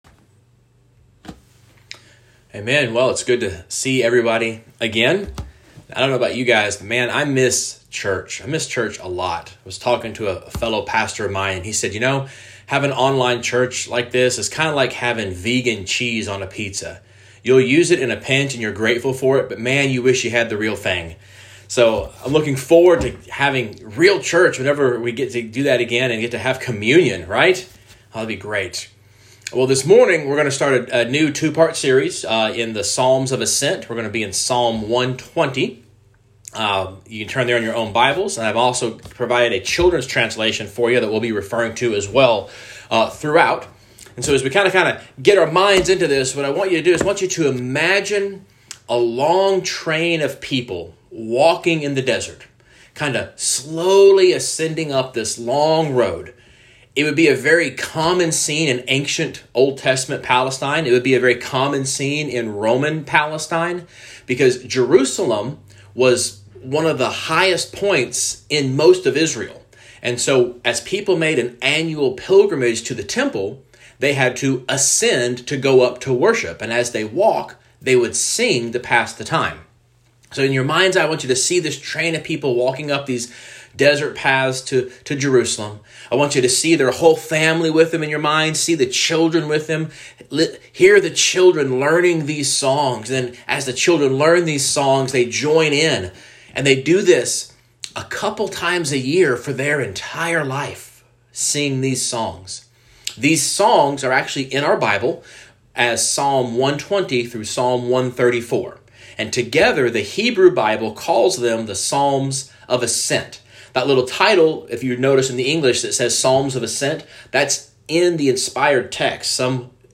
June 6, 2020 Guest Speaker Sermons series Weekly Sunday Service Save/Download this sermon Psalm 120 Other sermons from Psalm Deliver Me, O Lord A Song of Ascents. 120:1 In my distress […]